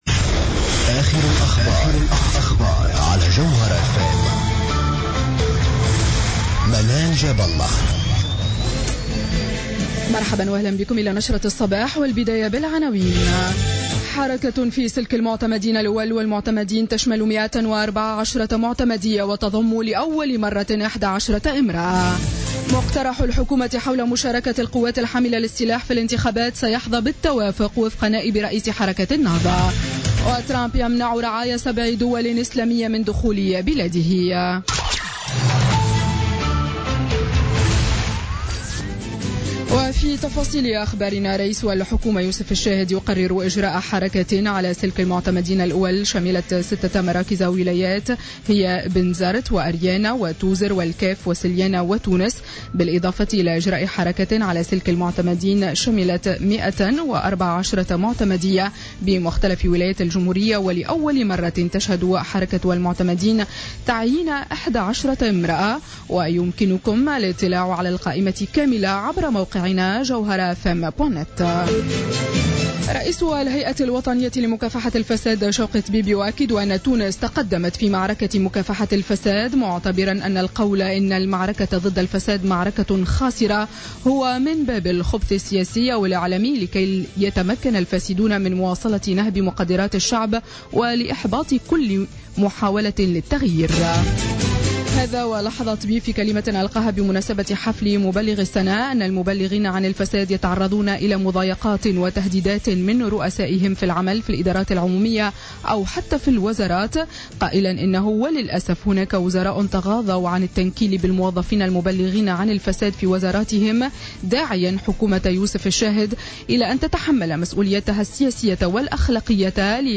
نشرة أخبار السابعة صباحا ليوم السبت 28 جانفي 2017